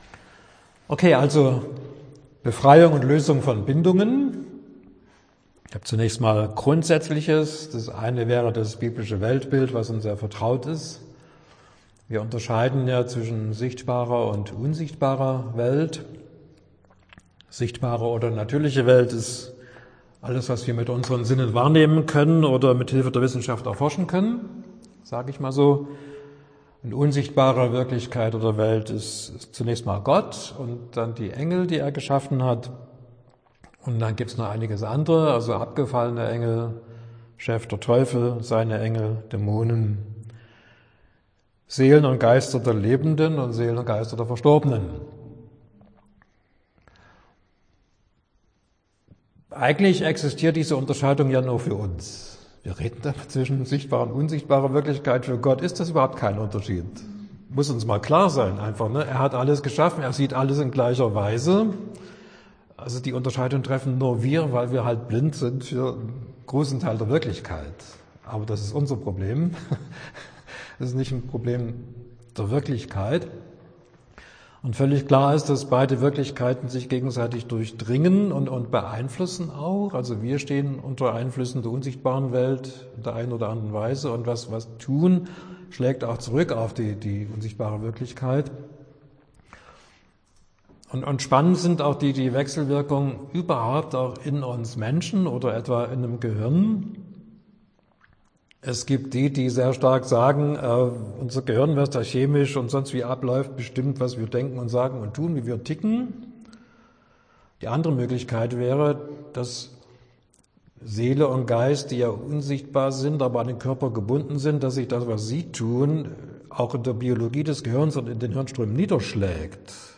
September 2025 Heruntergeladen 2 Mal Kategorie Audiodateien Vorträge Schlagwörter Heilung , Seelsorge , bindungen , befreiung , lösung , dämonen , Befreiungsdienst Beschreibung: Seelsorgeschulung Dauer des 1.